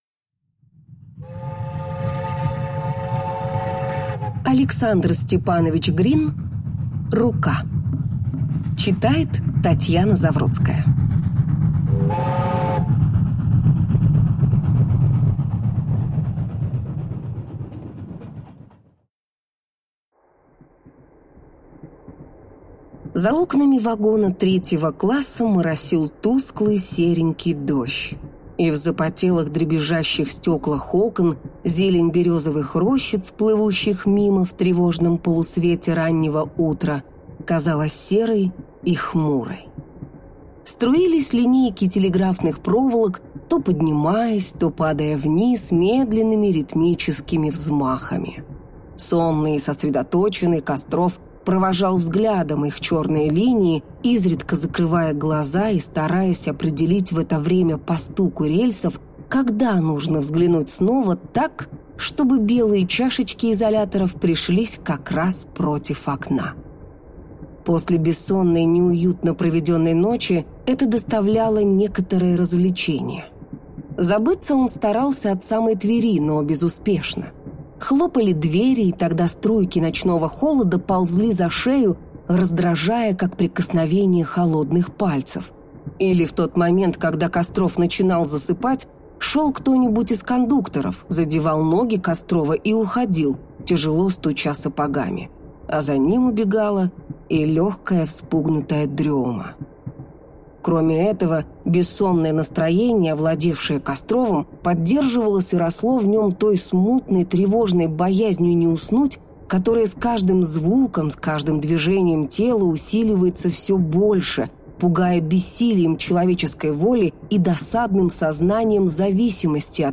Аудиокнига Рука | Библиотека аудиокниг